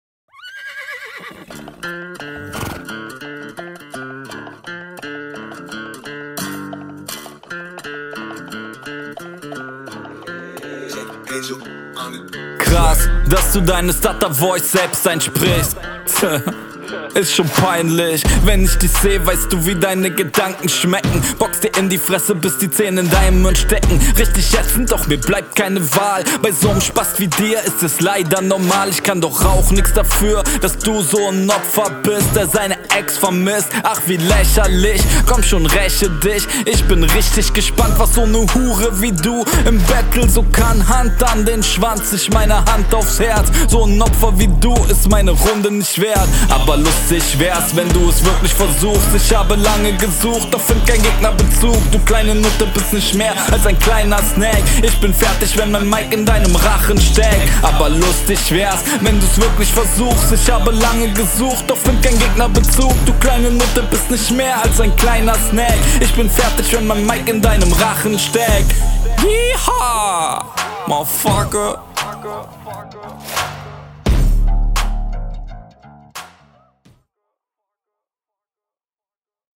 Den Beat find ich generell sehr cool.
Dieser Beat liegt dir schon weitaus besser.